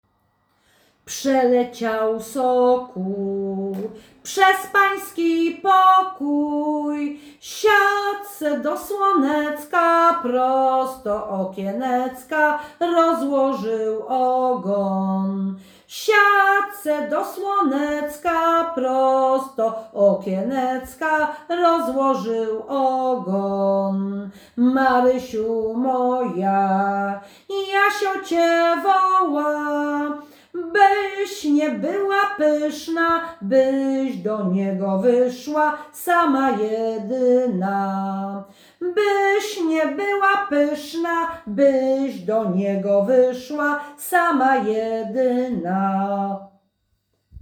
Przeleciał sokół – Żeńska Kapela Ludowa Zagłębianki
Nagranie współczesne